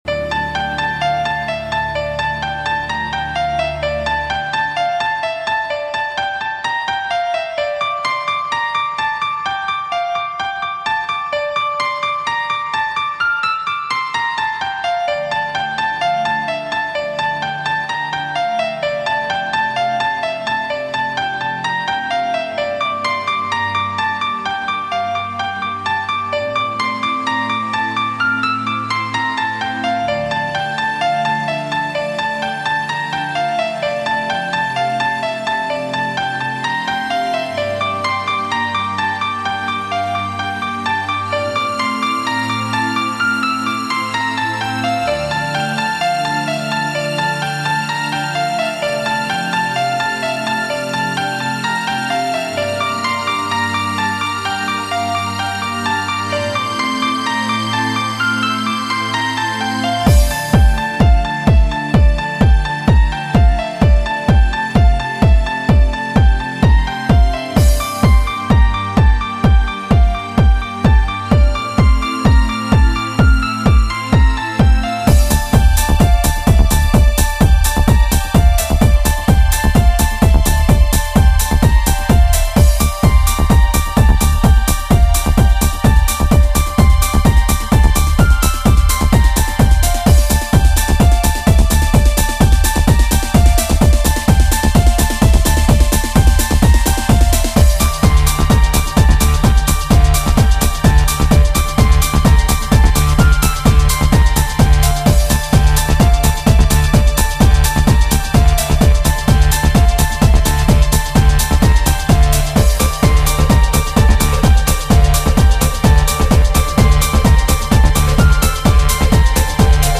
沙发音乐